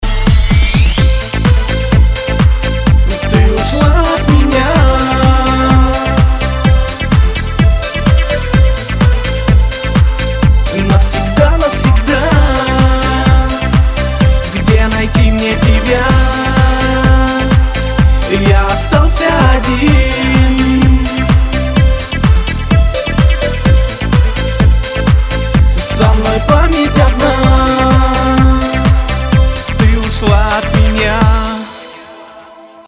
• Качество: 320, Stereo
поп
грустные
попса